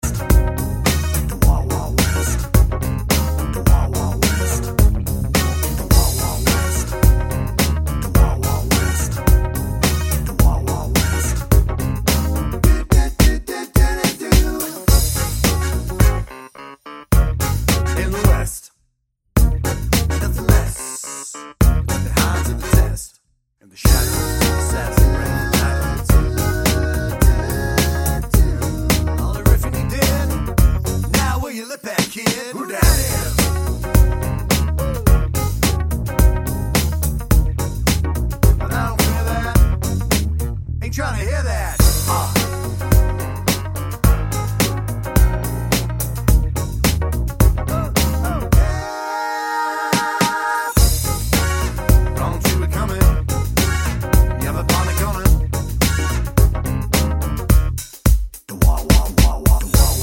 Duet Version R'n'B / Hip Hop 4:08 Buy £1.50